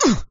die.mp3